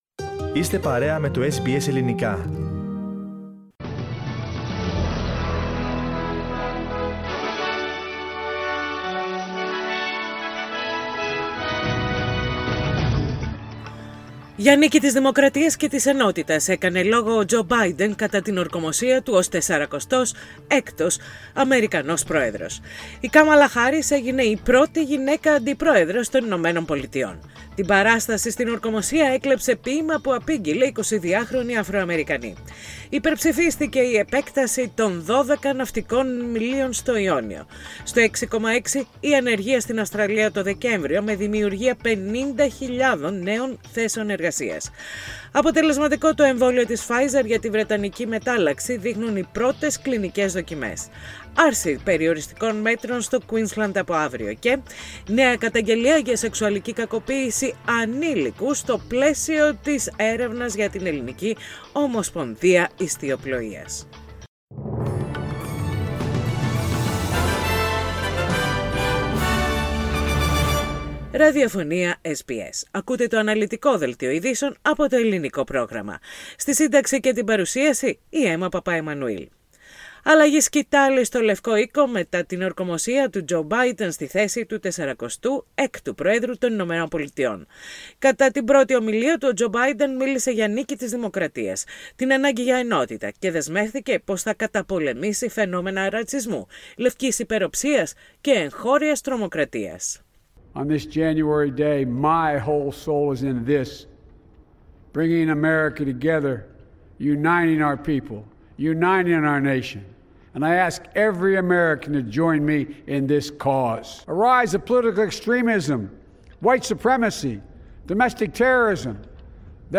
Δελτίο ειδήσεων - Πέμπτη 21.1.21
Οι κυριότερες ειδήσεις της ημέρας από το Ελληνικό πρόγραμμα της ραδιοφωνίας SBS.